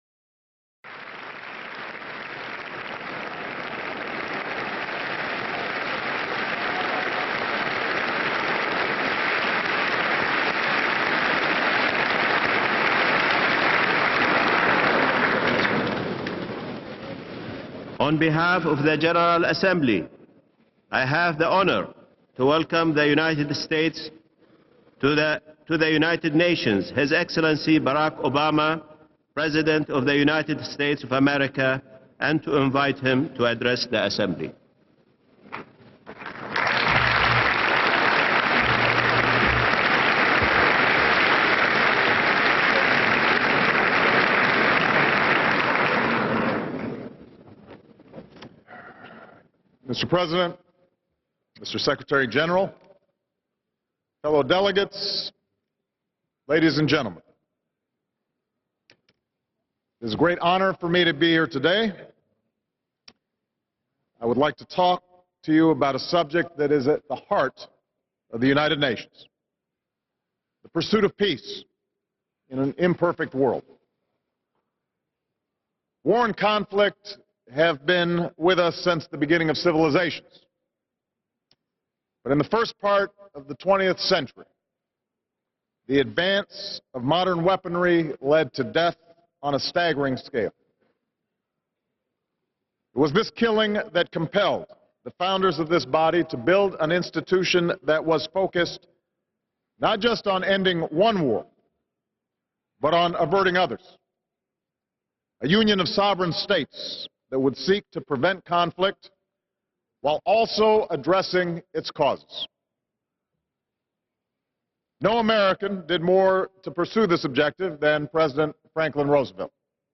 U.S. President Barack Obama addresses the United Nations General Assembly